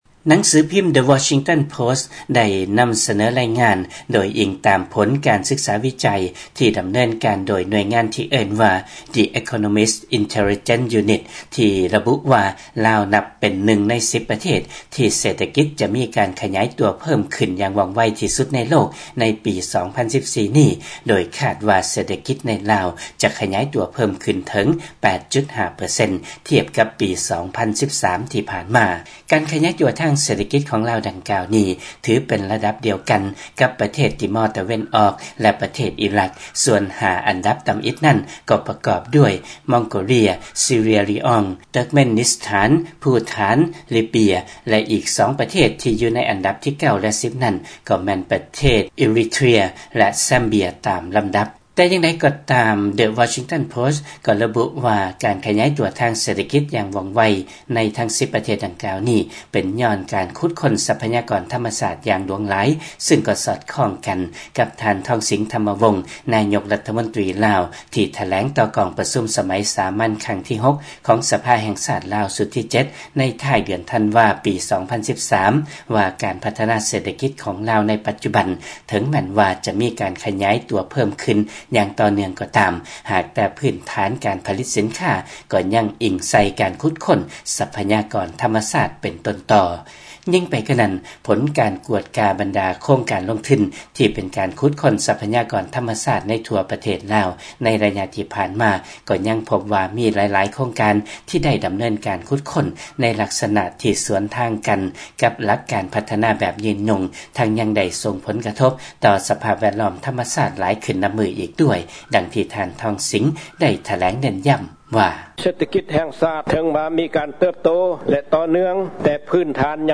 ຟັງລາຍງານ ລາວເປັນນຶ່ງປະເທດ ທີ່ເສດຖະກິດຂະຫຍາຍຕົວໄວທີ່ສຸດໃນໂລກ